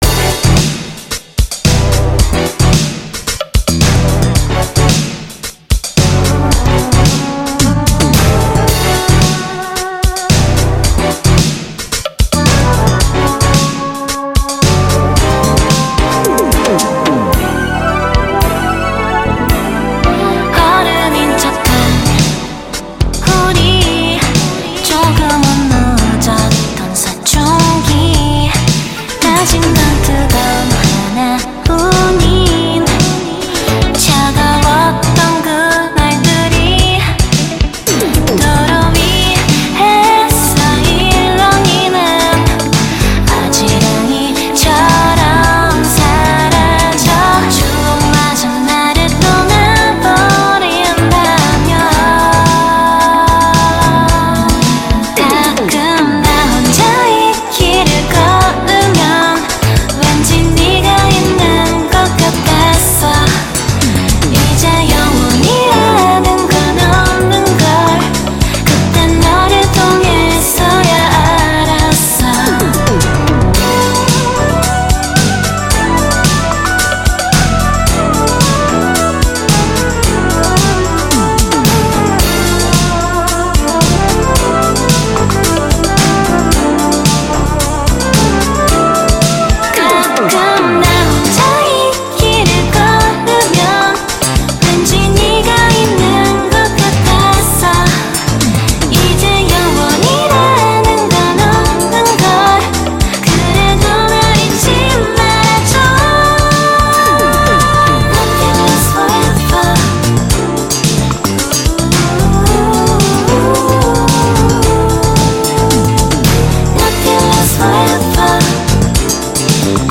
BPM111
Audio QualityPerfect (High Quality)
Enjoy stepping to bass licks.